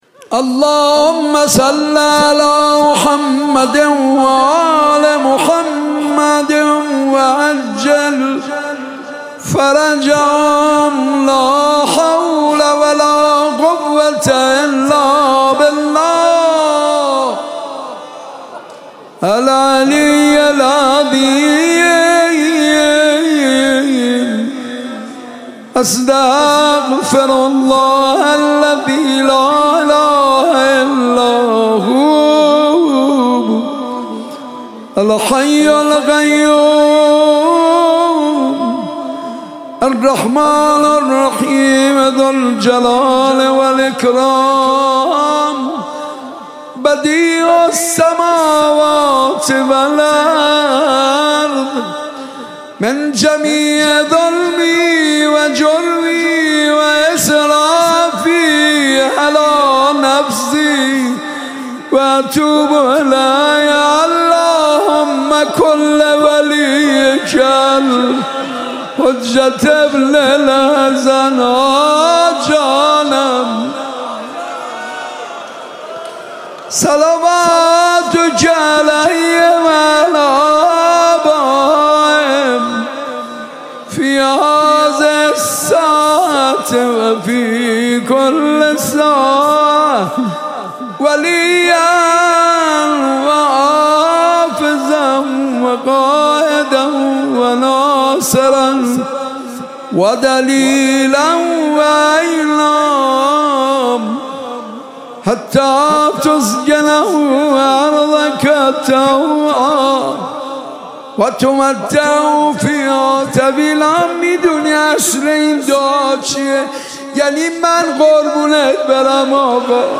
دانلود مناجات زیبا و دلنشین از حاج منصور ارضی در شب بیست و سوم ماه رمضان و شب قدر به همراه متن